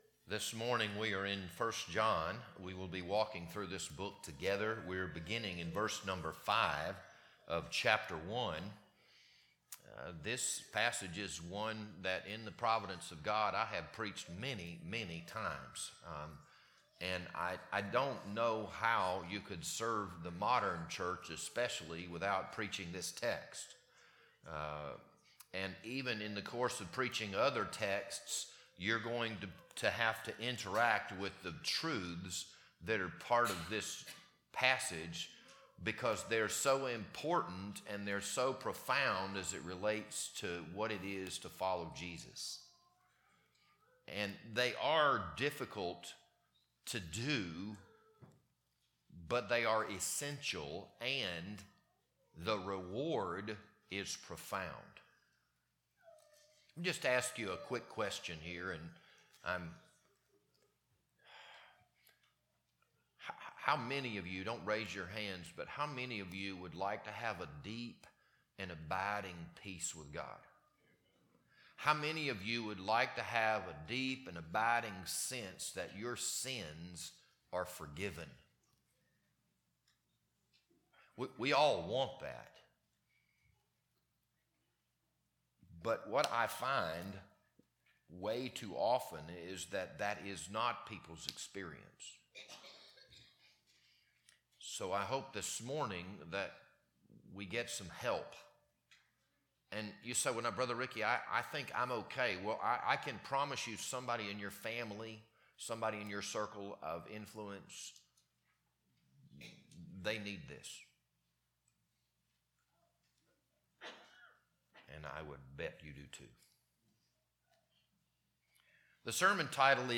This Sunday morning sermon was recorded on January 11th, 2026.